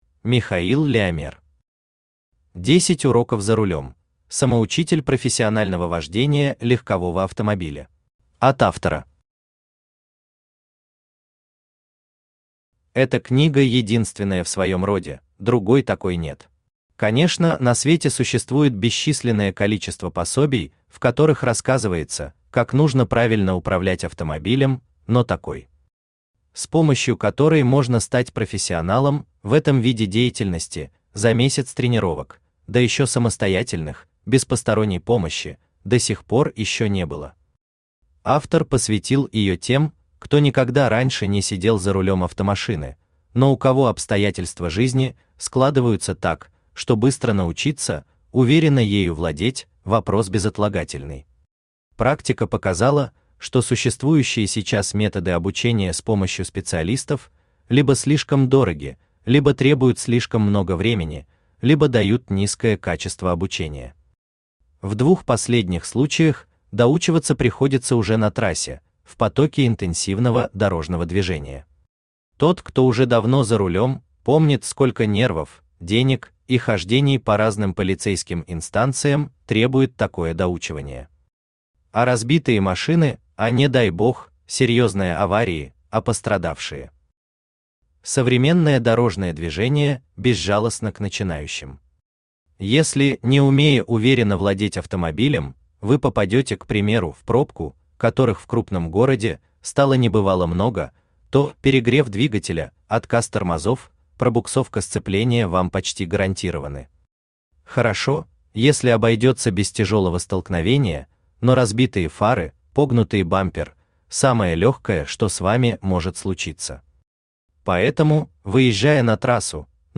Аудиокнига 10 уроков за рулём. Самоучитель профессионального вождения легкового автомобиля | Библиотека аудиокниг
Самоучитель профессионального вождения легкового автомобиля Автор Михаил Леомер Читает аудиокнигу Авточтец ЛитРес.